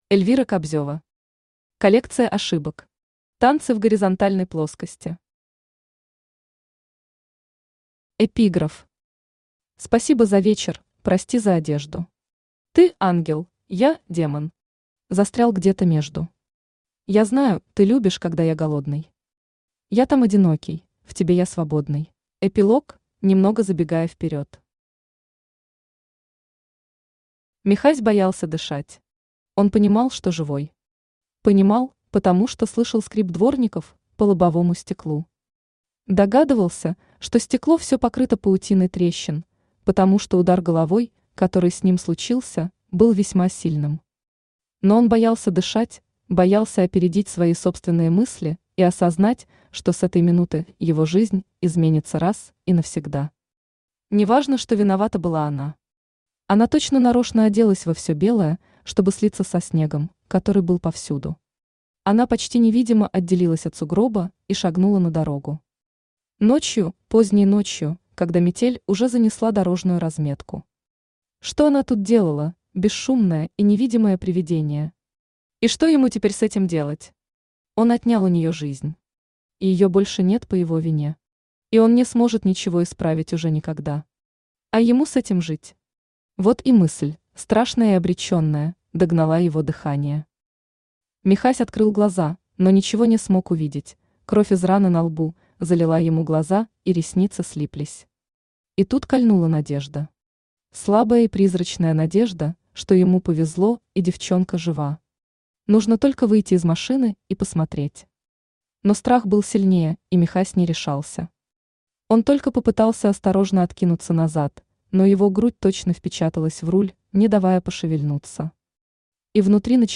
Аудиокнига Коллекция ошибок. Танцы в горизонтальной плоскости | Библиотека аудиокниг
Aудиокнига Коллекция ошибок. Танцы в горизонтальной плоскости Автор Эльвира Кобзева Читает аудиокнигу Авточтец ЛитРес.